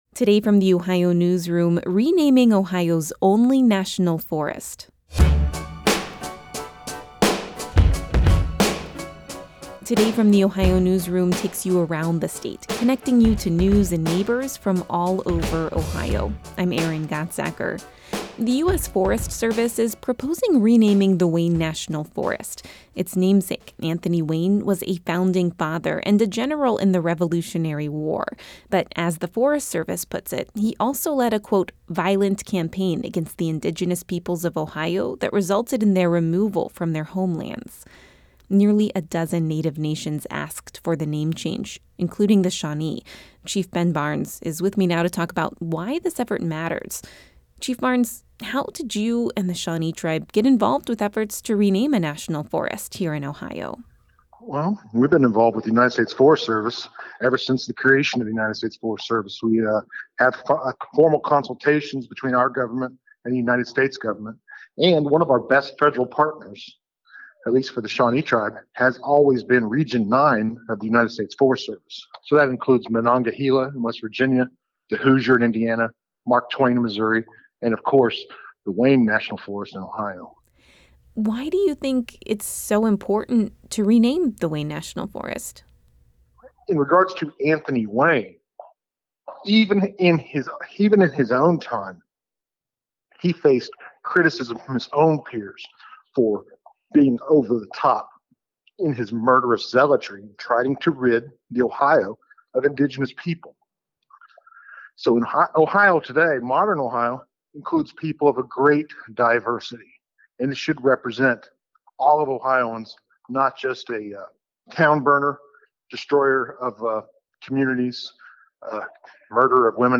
This interview has been lightly edited for clarity and brevity.